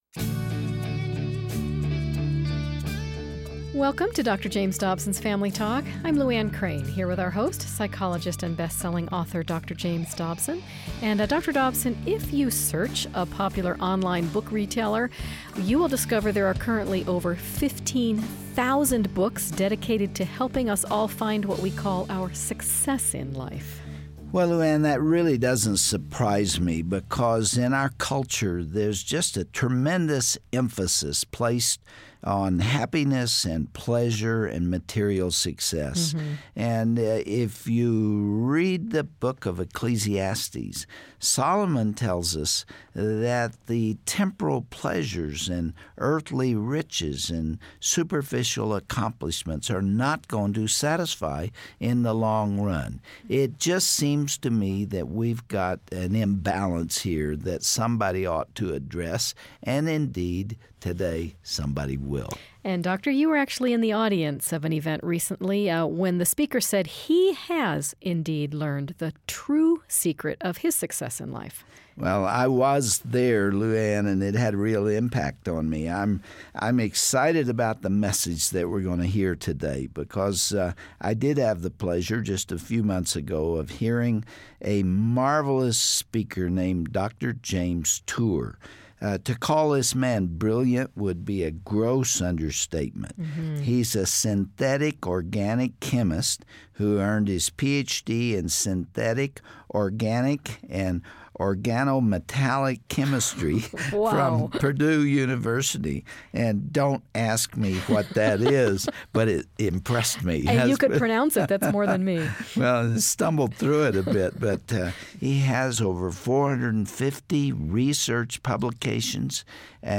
Have you thought lately about the joy, peace, and blessings that can be found by reading the Scriptures? Hear a brilliant scientist and inventor talk about how God's Word was the key to his success AND his happiness.